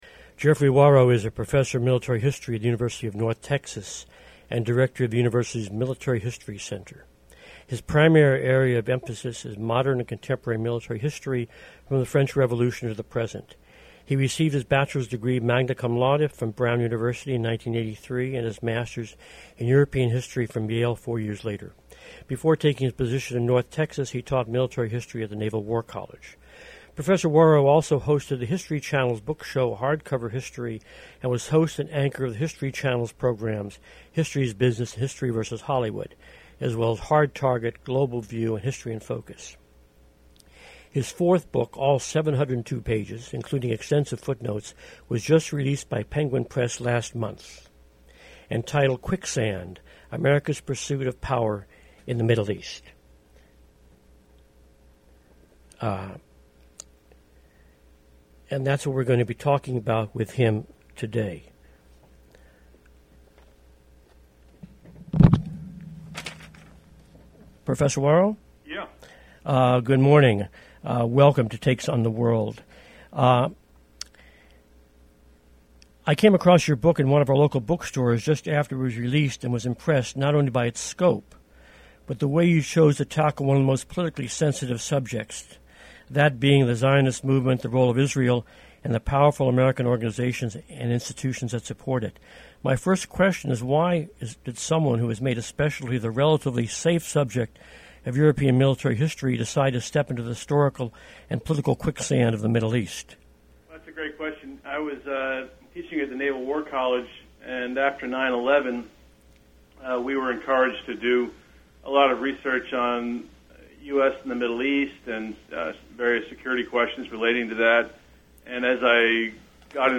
The above is a presentation at the National Summit to Reassess the U.S.-Israel “Special Relationship” on March 7, 2014 at the National Press Club.